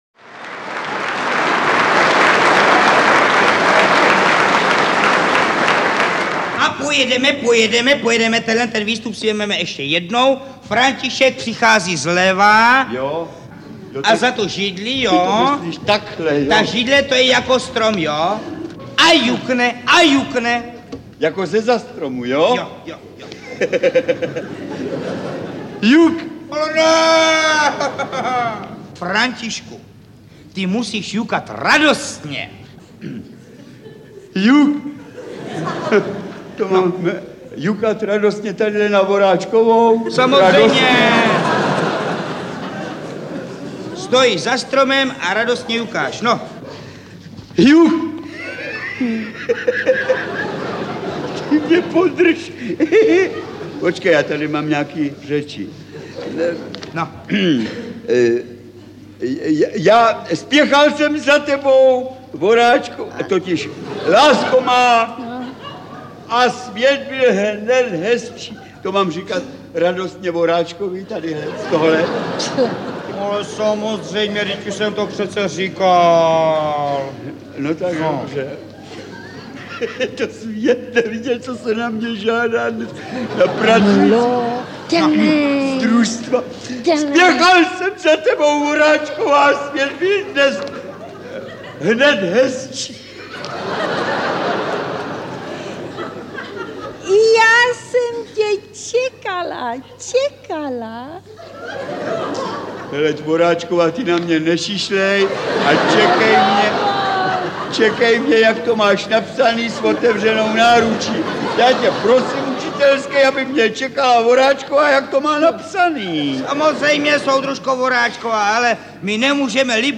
Nejlepší scénky a výstupy našich předních komiků a hereckých osobností vybrané ze silvestrovských pořadů a estrád nedávných let vás příjemně překvapí a pobaví.
stáhnout ukázku Varianty: Vyberte Audiokniha 69 Kč Další informace: Čte: Josef Beyvl, Marie Rosůlková, Zdeněk…